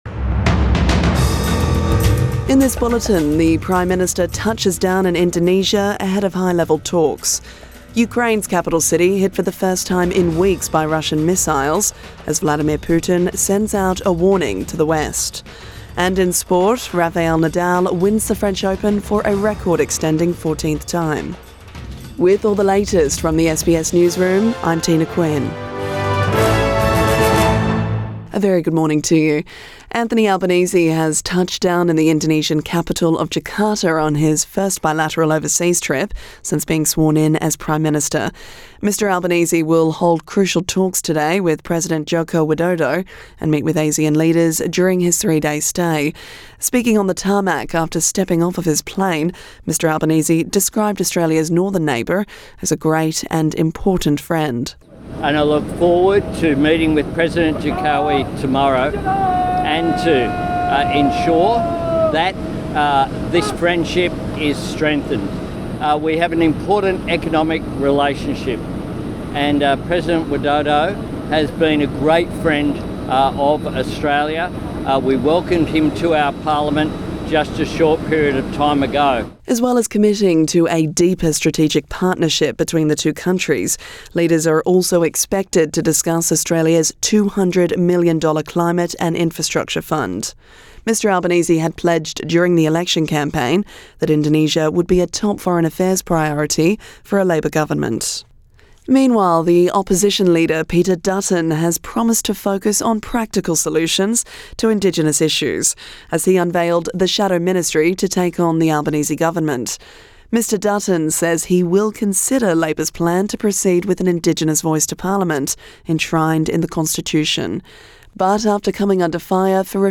AM bulletin 6 June 2022